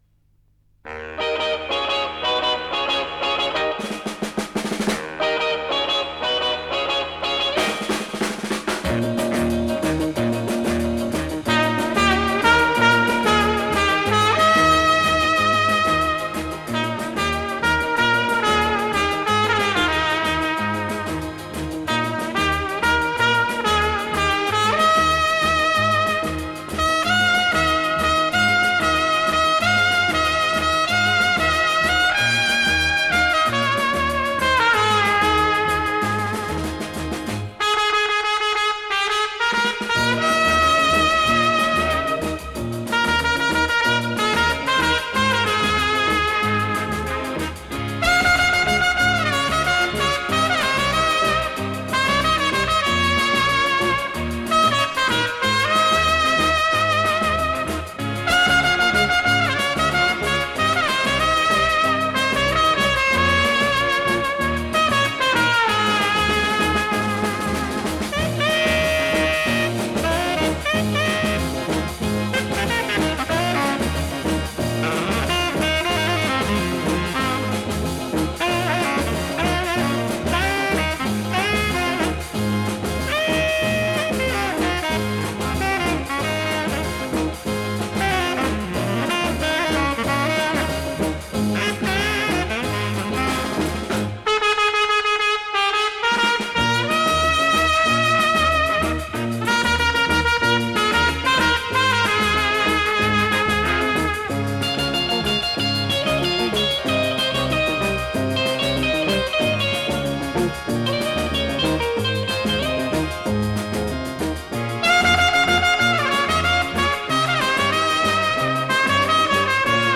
мр3 320 (моно)
Французский трубач, руководитель оркестра.